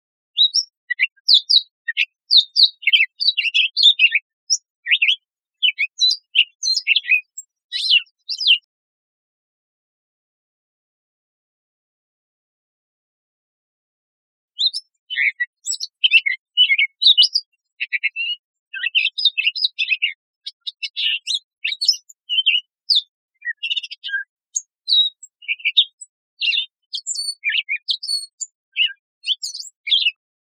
Gray Catbird | Ask A Biologist